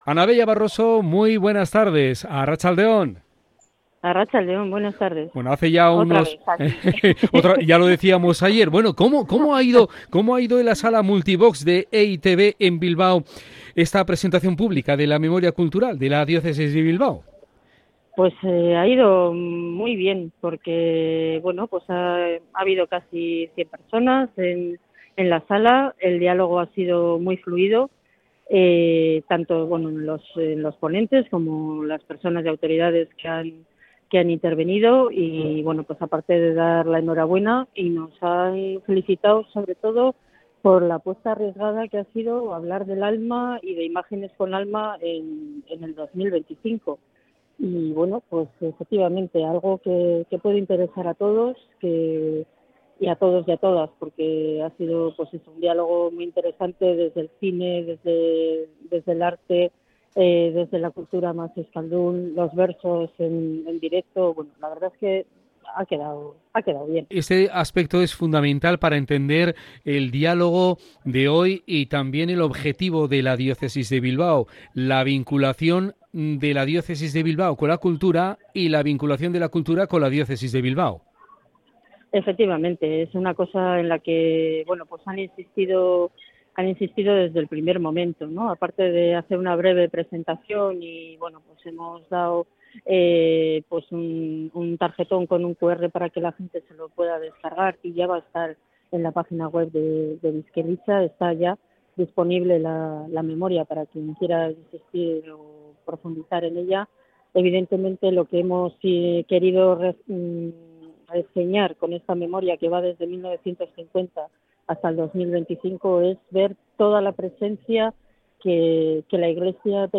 En la presentación pública de la Memoria Cultural